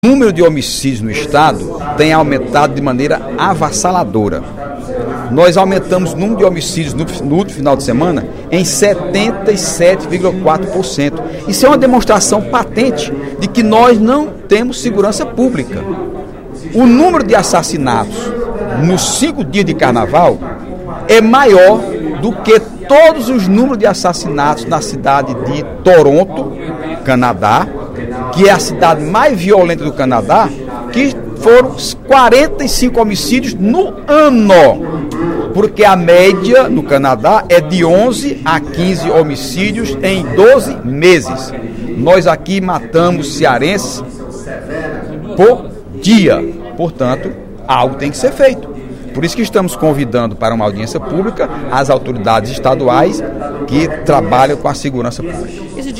Na sessão plenária desta sexta-feira (15/02), o deputado Heitor Férrer (PDT) reclamou da banalização da violência no Ceará, ao destacar o aumento do número de homicídios no Carnaval deste ano em relação a 2012.